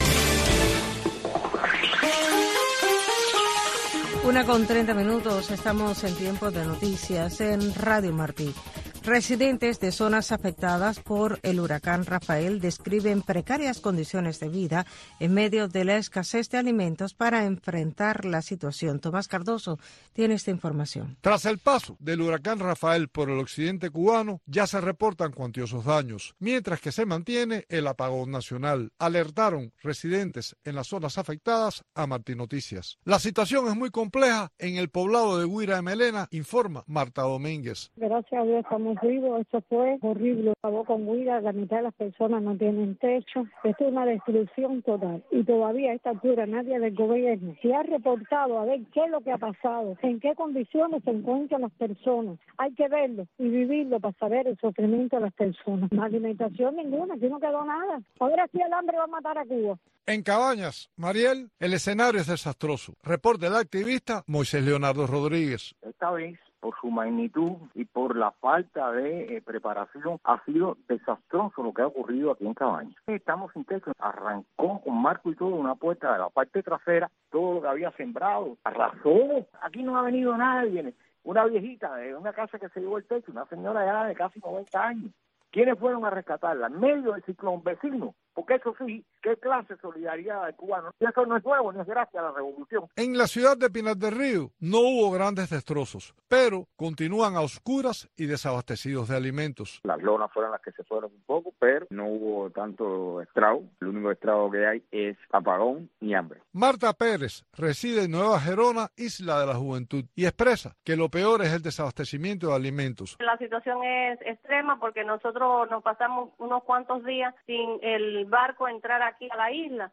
Una mirada a la situación migratoria, para analizar las leyes estadounidenses, conversar con abogados y protagonistas de este andar en busca de libertades y nuevas oportunidades para lograr una migración ordenada y segura.